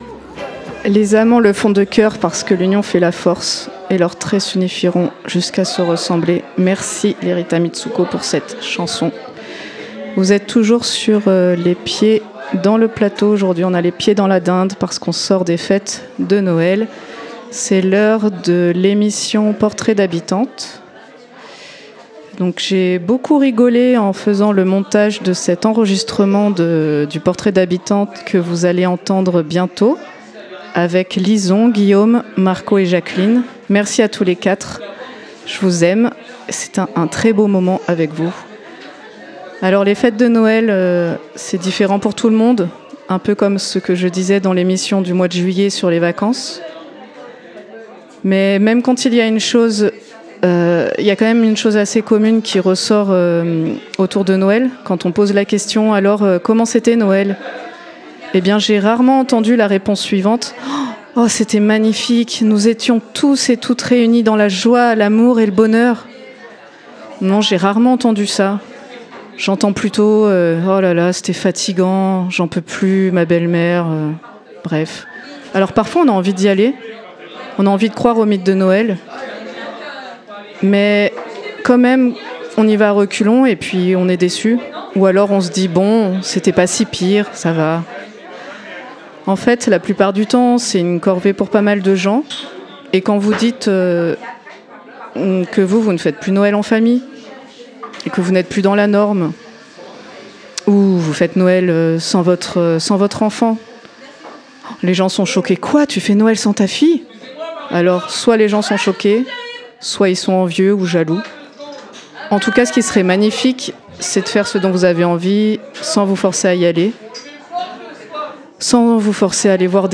Non: Stéphane Bern Je vous transmets l'interview dont ma fille et moi avons été sujettes récemment, par une animatrice de la radio locale.